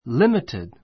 limited límitid